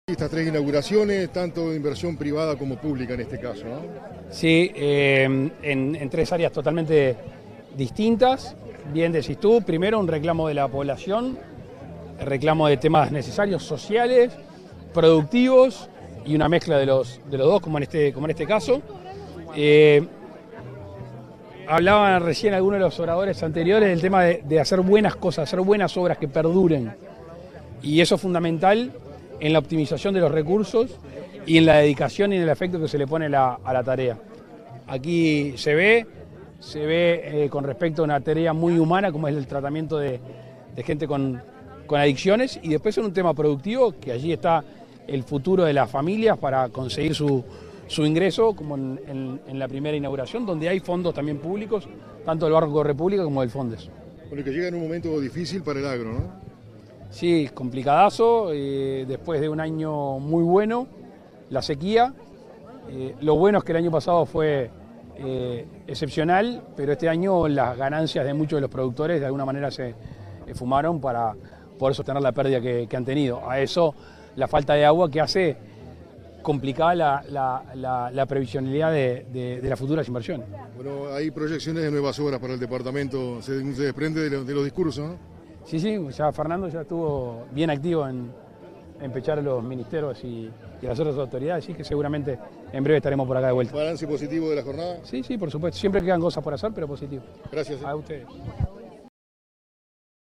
Declaraciones del presidente Lacalle Pou en Trinidad
Declaraciones del presidente Lacalle Pou en Trinidad 02/06/2023 Compartir Facebook X Copiar enlace WhatsApp LinkedIn Tras participar en la inauguración del circuito 2 de Caminos Productivos, en la Escuela Agraria de Trinidad, este 2 de junio, el presidente de la República, Luis Lacalle Pou, realizó declaraciones a la prensa.